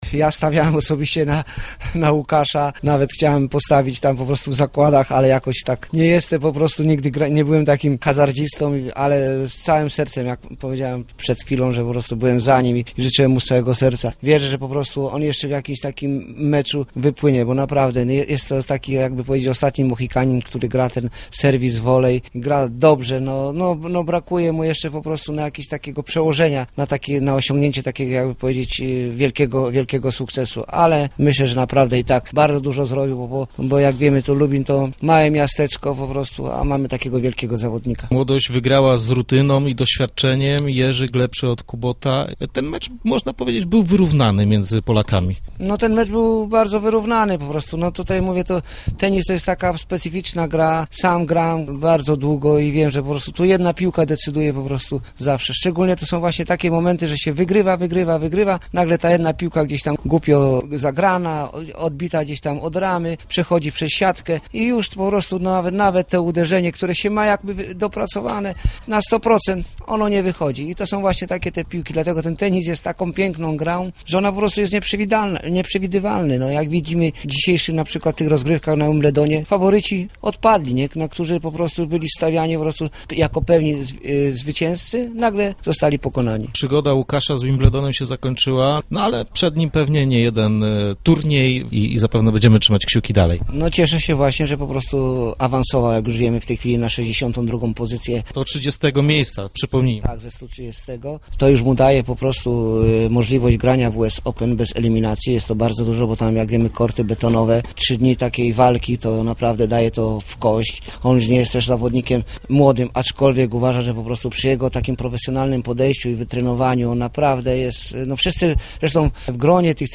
Tuż po zakończeniu meczu rozmawialiśmy z